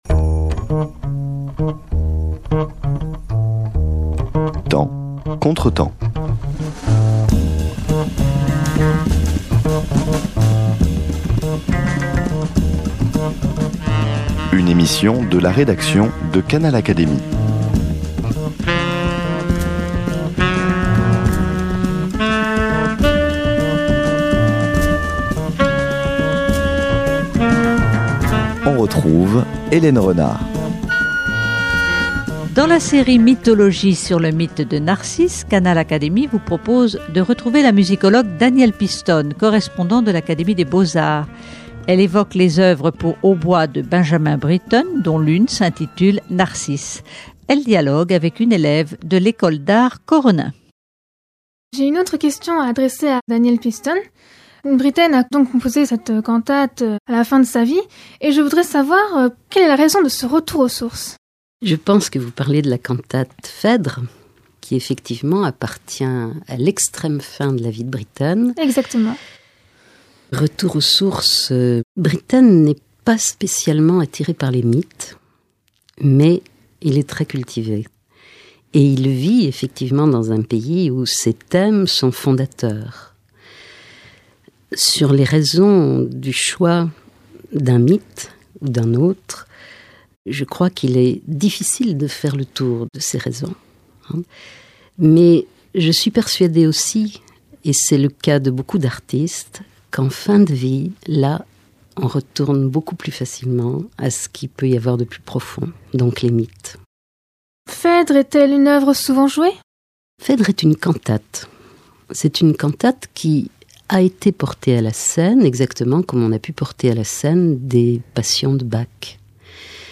- Prochainement seront mises en ligne d'autres émissions sur les mythes dans l'art, la littérature, la musique, en collaboration avec les élèves de l'école d'art Koronin, à Paris, qui ont travaillé sur ces mythes et ont interrogé les experts invités dans notre studio.